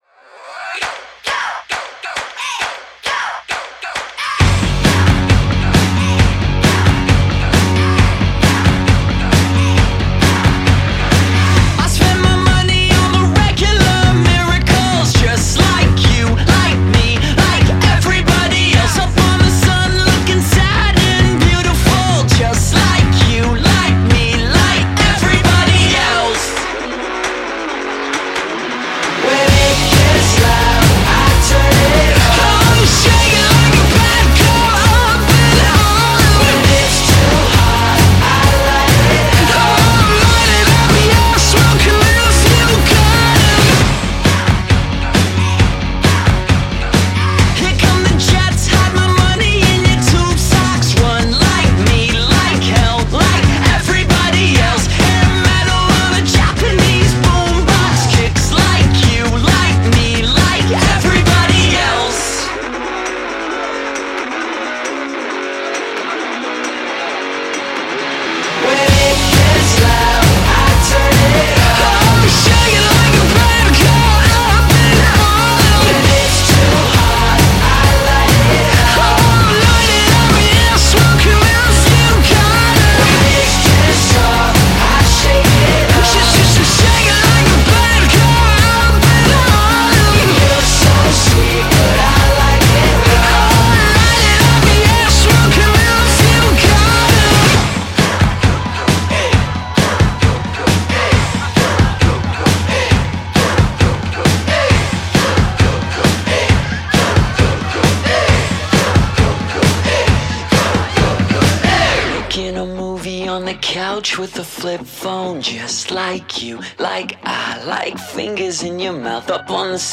is a rousing, contagious bit of fun